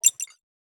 HiTech Click 3.wav